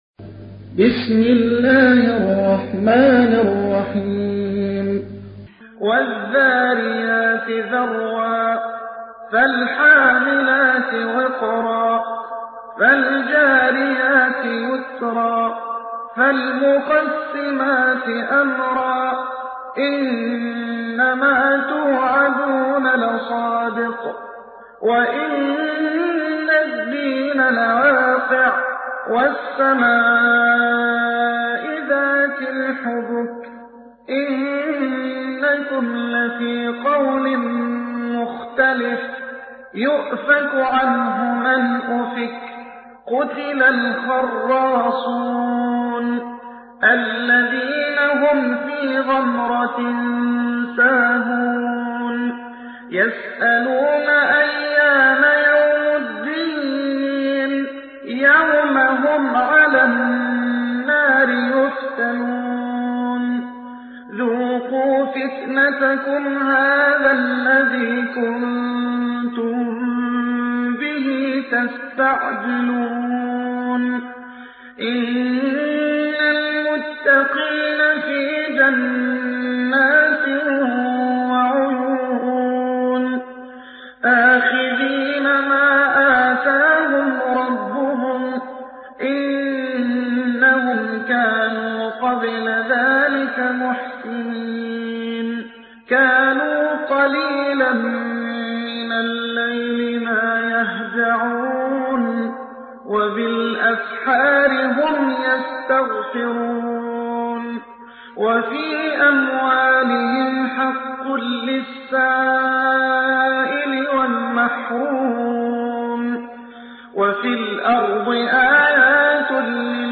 تحميل : 51. سورة الذاريات / القارئ محمد حسان / القرآن الكريم / موقع يا حسين